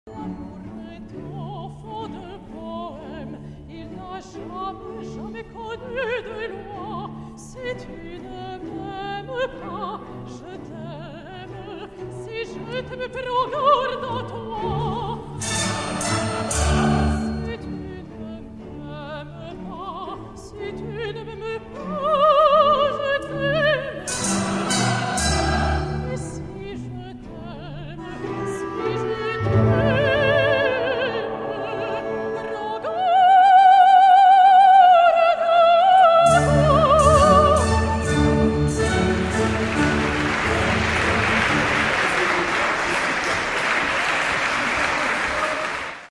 Bizet’s Carmen—exclusively performed at Opera National de Paris. Relive a bold moment of lyrical and acoustic prowess with a stunning orchestra of 16 Devialet Phantom I. Mezzo-soprano